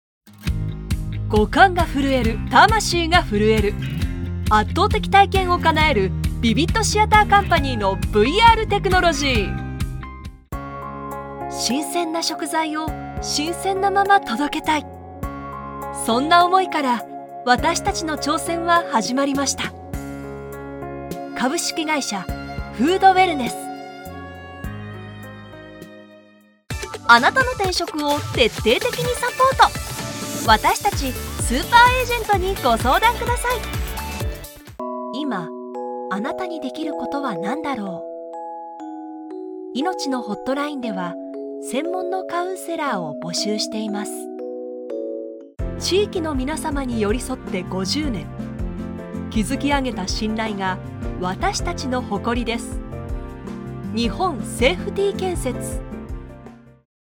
Japanese voice over, Japanese voice, Japanese commercial, corporate, business, documentary, e-learning, product introductions
Sprechprobe: Industrie (Muttersprache):
Corporate_7.mp3